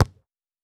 Ball Pass Long.wav